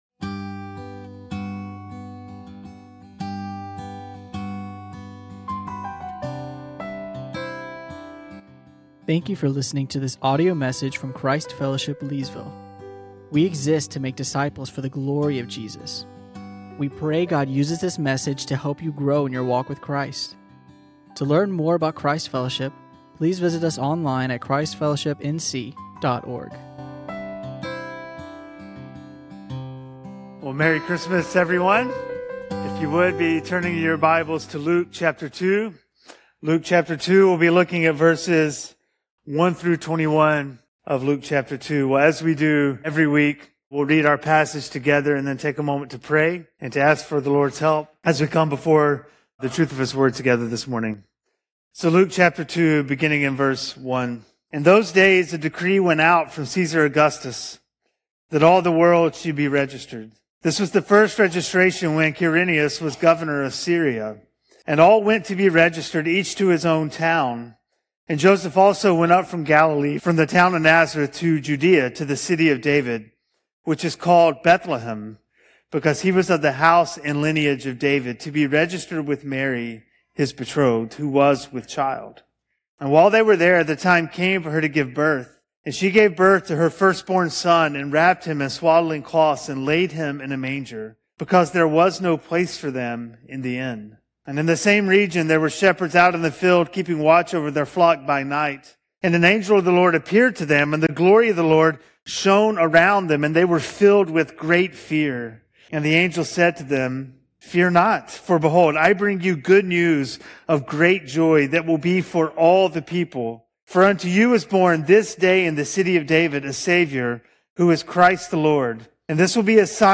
teaches on Luke 2:1-21.